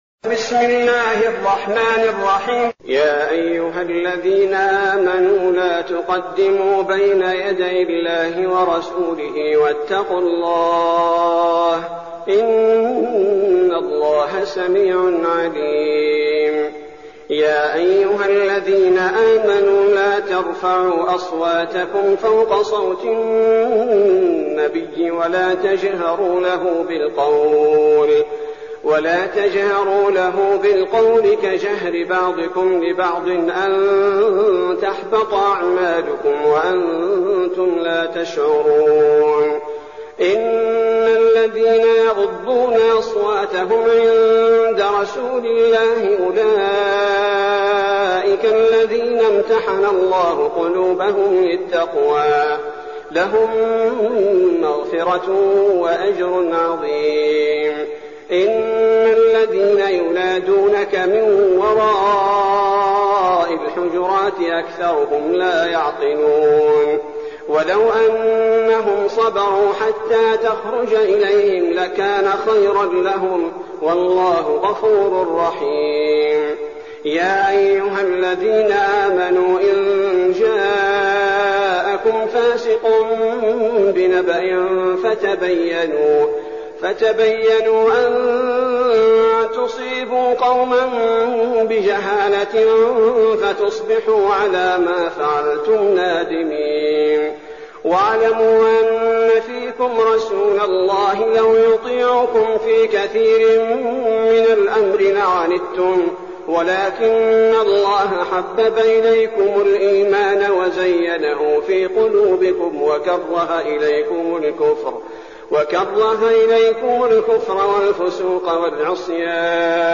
المكان: المسجد النبوي الشيخ: فضيلة الشيخ عبدالباري الثبيتي فضيلة الشيخ عبدالباري الثبيتي الحجرات